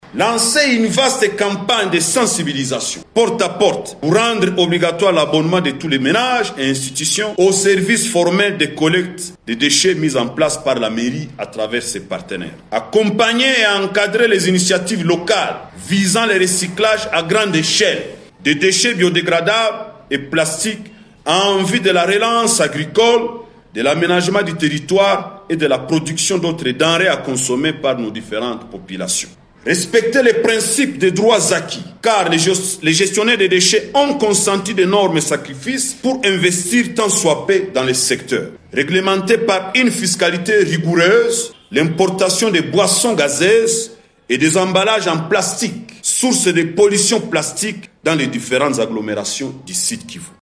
Message de la synergie des organisations d’assainissement SOA en sigle dans une déclaration rendue publique devant la presse ce mercredi 10 octobre 2024  au Bureau de Coordination de la Société Civile du Sud-Kivu.